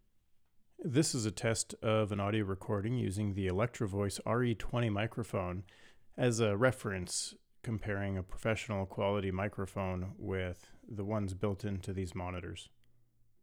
For those wondering about the microphone quality, I compared the U3224KB, Studio Display, and my RE20 dynamic microphone.
The RE20 sounds the best, obviously.
EV RE20